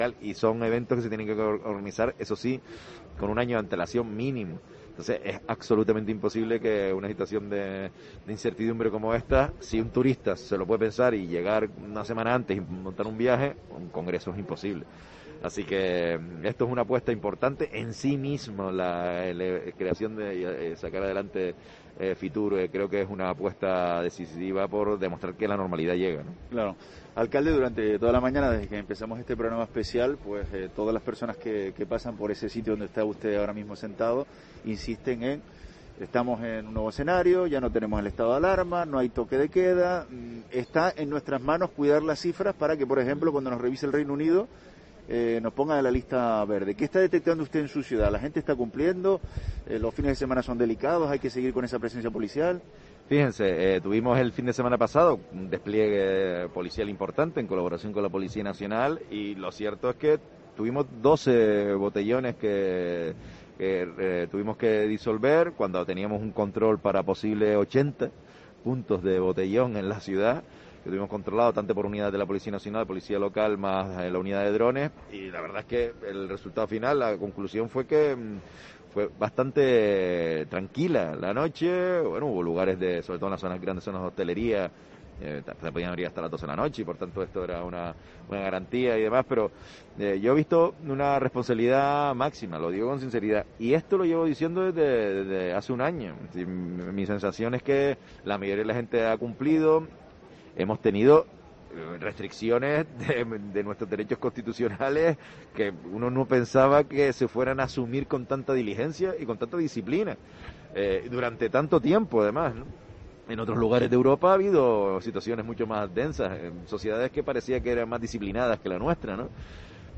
Entrevista del alcalde de Las Palmas de Gran Canaria en FITUR